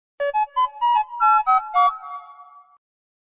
3. Звонок по телефону